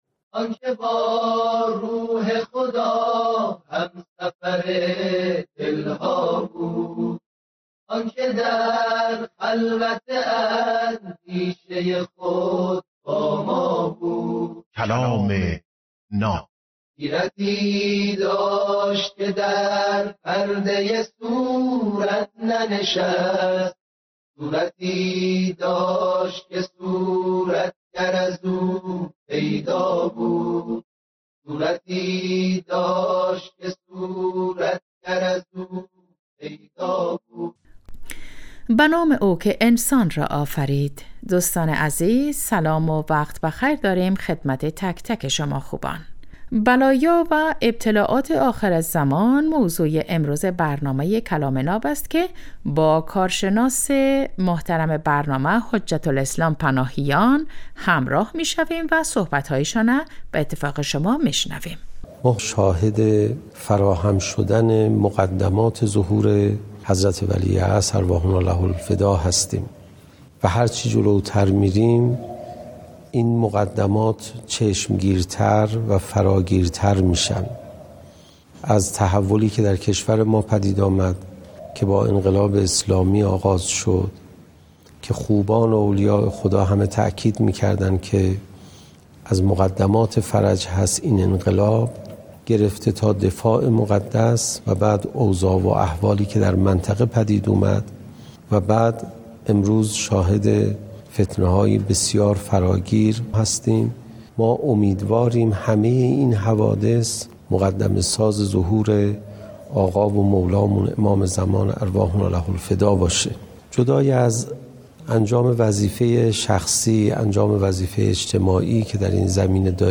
کلام ناب برنامه ای از سخنان بزرگان است که هر روز ساعت 7:35 عصر به وقت افغانستان به مدت 10دقیقه پخش می شود.